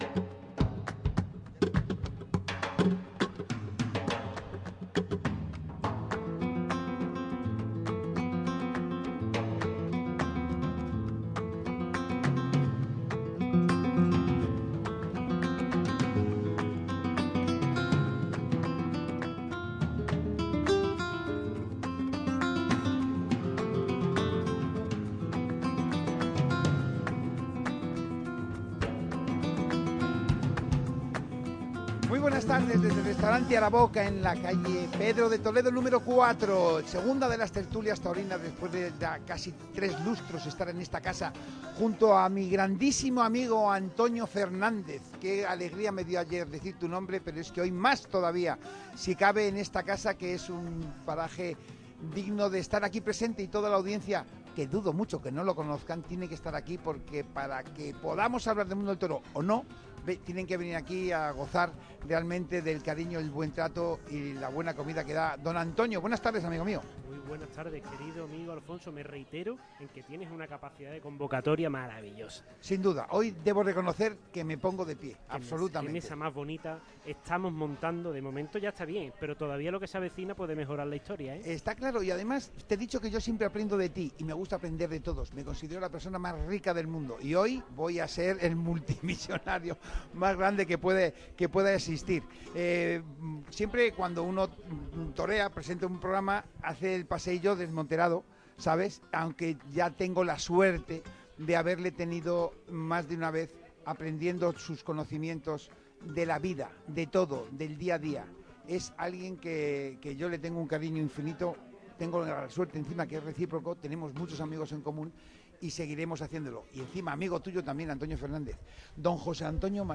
Primera sábado de Feria de Málaga y gran tertulia taurina en Araboka - Radio Marca Málaga
También participó vía telefónica el matador de toros pacense